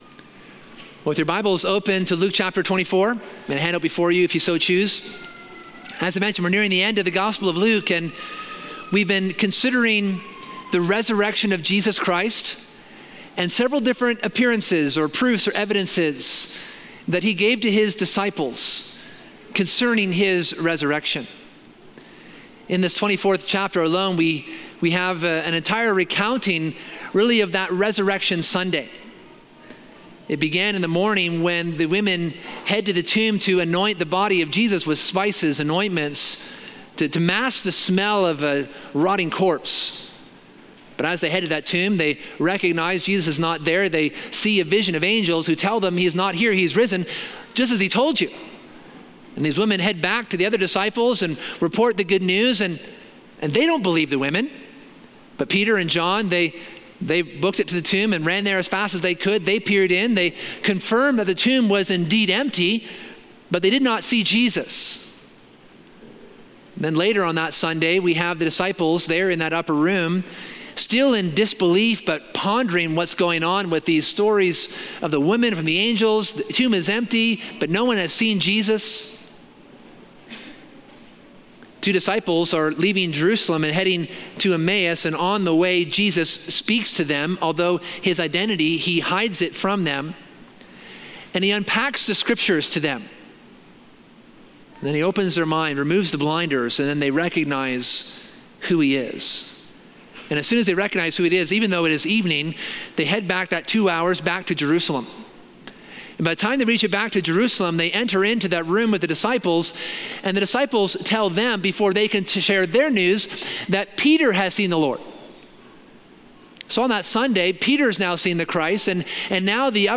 The sermon examines the encounter of the disciples with the risen Christ on the evening of the resurrection.